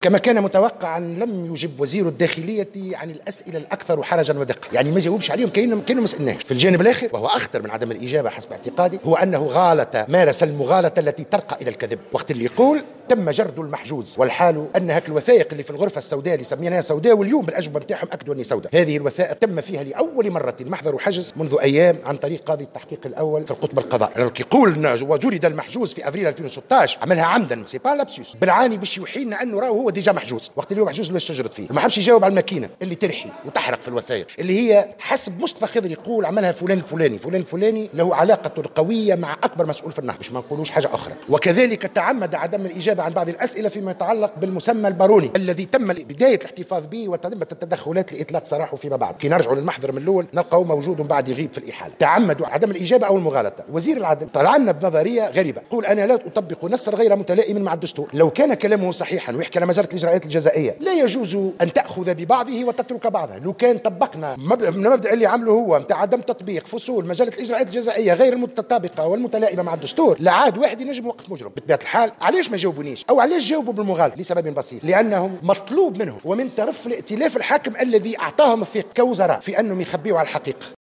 وأضاف في تصريح لمراسلة "الجوهرة اف أم" أنه تعمد المغالطة وإخفاء الحقائق وحجبها والتلاعب بالملفات.